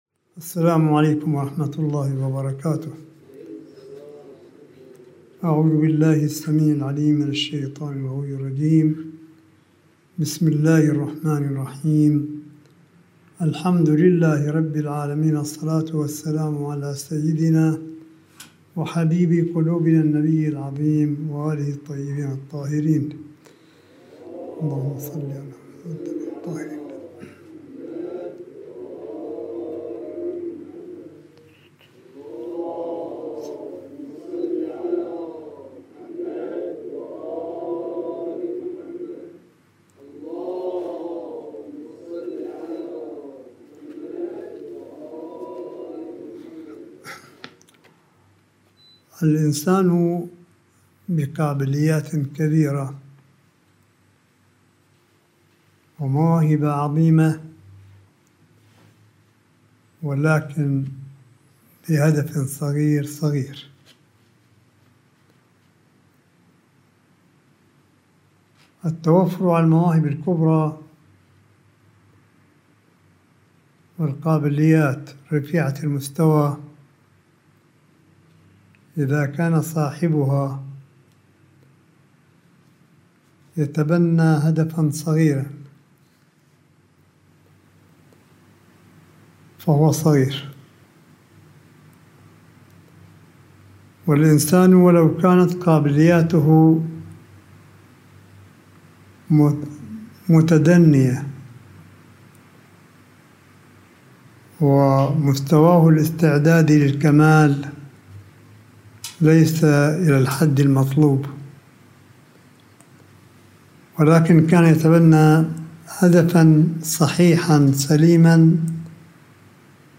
ملف صوتي لكلمة سماحة آية الله الشيخ عيسى أحمد قاسم في المجلس القرآني الذي عُقد في منزل سماحته بقم المقدسة ليلة الجمعة المباركة وسط حضور المؤمنين – 13 رمضان 1443هـ / 15 أبريل 2022م